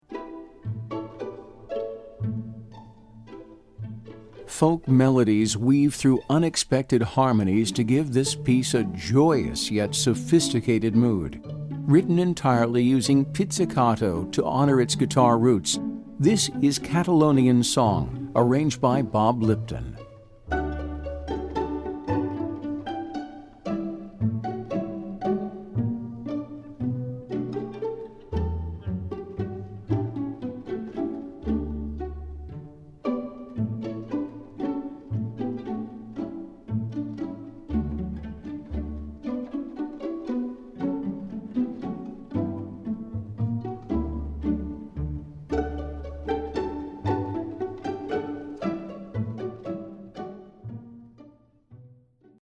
Composer: Spanish Folk Song
Voicing: String Orchestra